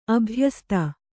♪ abhyasta